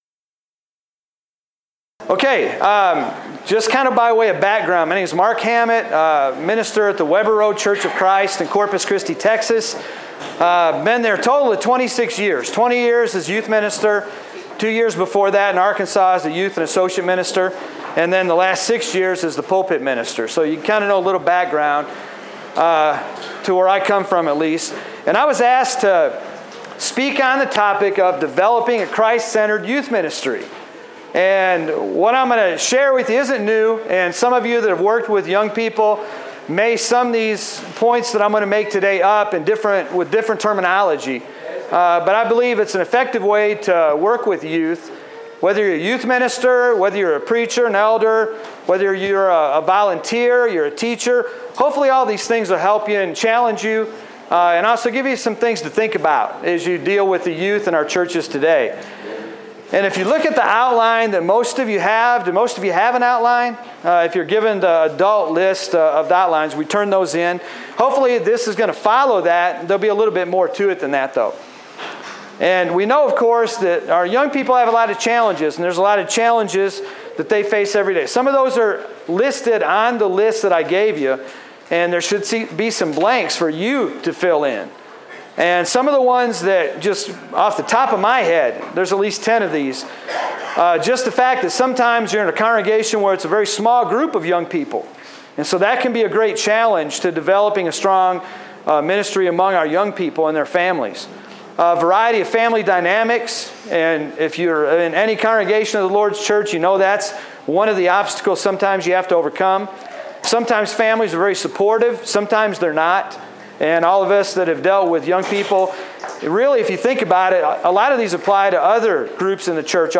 Event: Discipleship U 2016
lecture